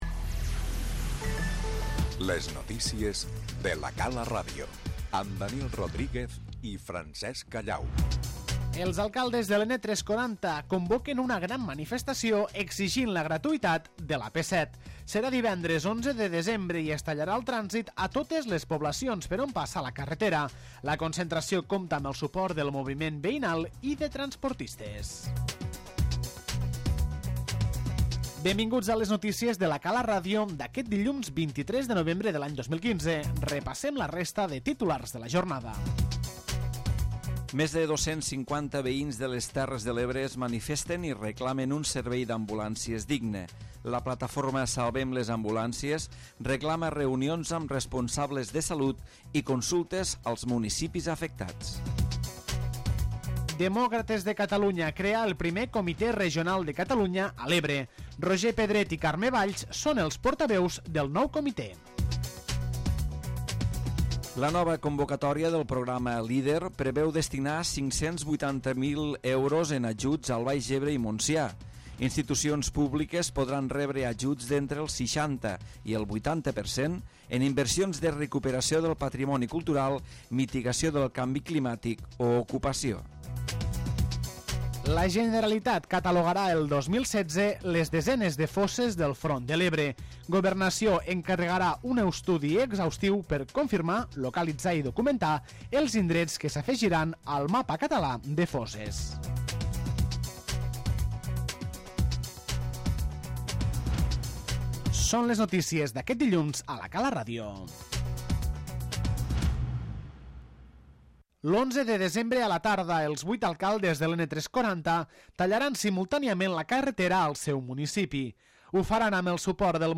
La convocatòria d'una gran manifestació a l'N-340 pel pròxim 11 de desembre obre avui l'informatiu de la Cala Ràdio.